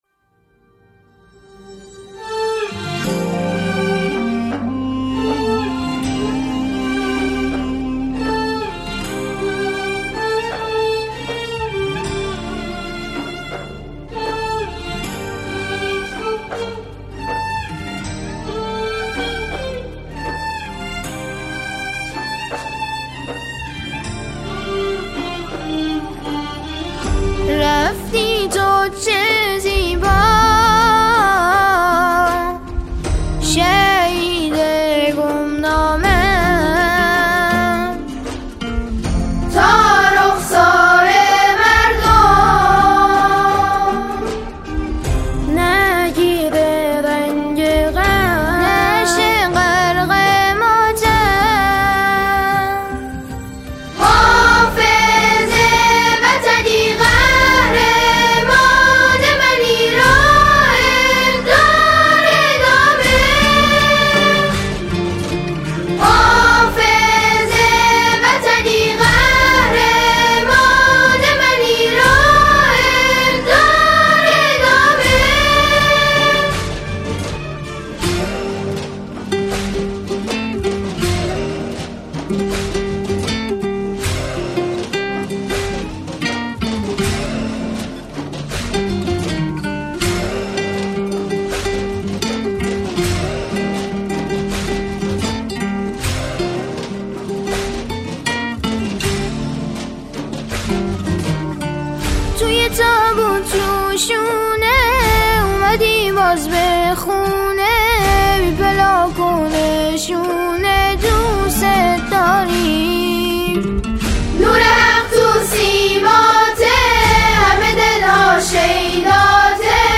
سرودهای شهدا